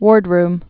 (wôrdrm, -rm)